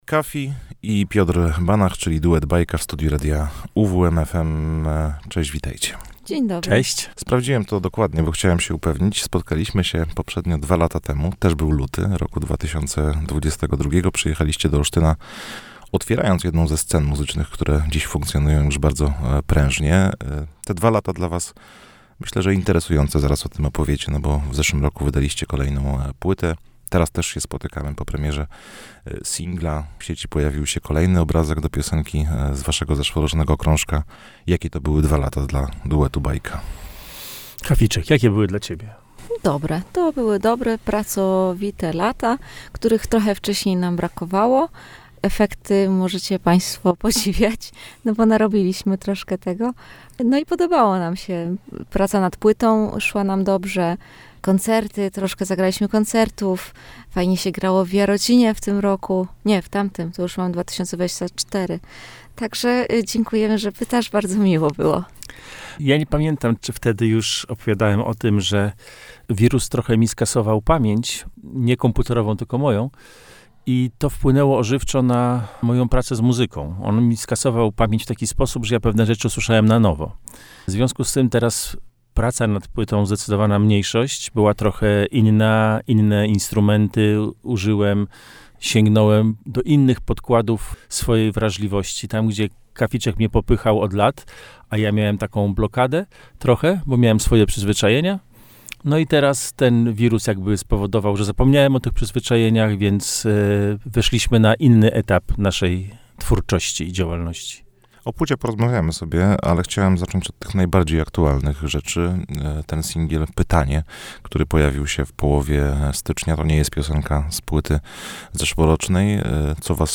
O pracy nad nią i kolejnych planach opowiadali w studiu Radia UWM FM.